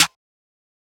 {Snare} RightWay.wav